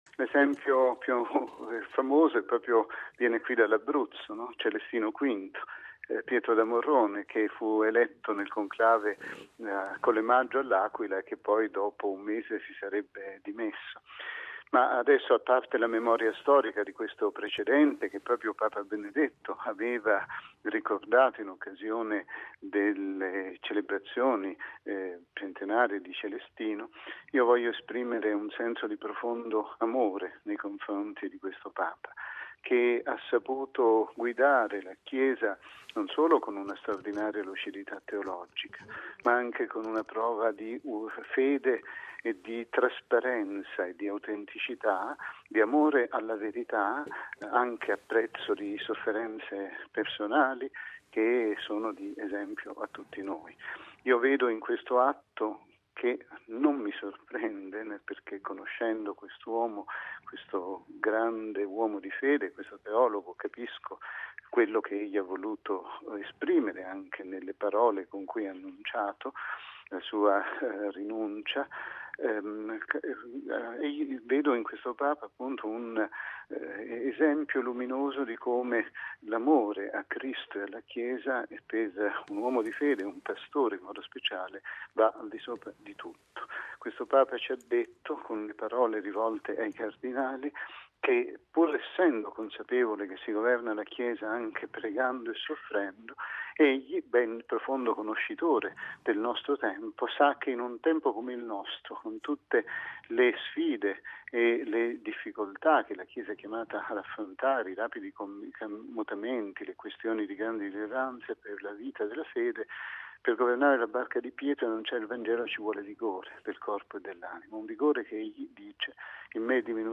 ◊   Sulla scelta di Benedetto XVI si sofferma anche l'arcivescovo di Chieti-Vasto, mons. Bruno Forte, che ricorda come il precedente più noto di rinuncia al ministero petrino provenga dalla sua regione: